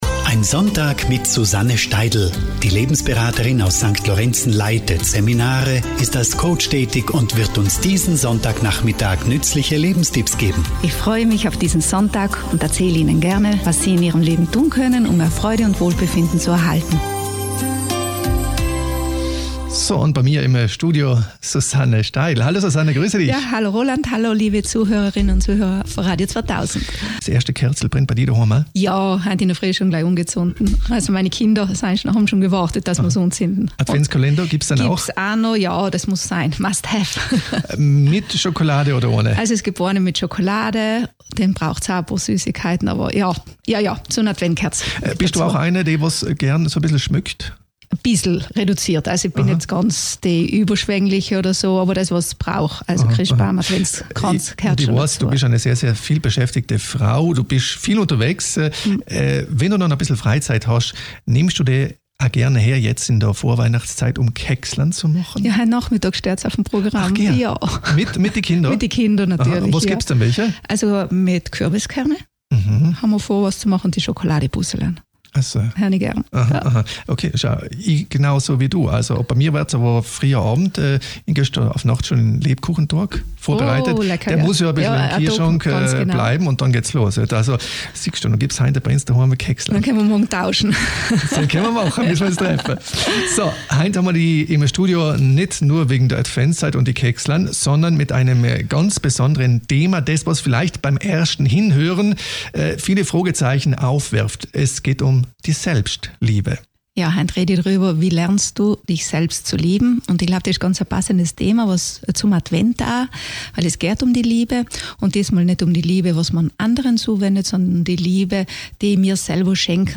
In dieser Sendung von Radio 2000 spreche ich über die SELBSTLIEBE. Du kannst erfahren, wieso es einem an Selbstliebe mangelt, welche Anzeichen es darfür gibt. Du bekommst viele praktische Tipps, wie du lernen kannst dich mehr zu lieben.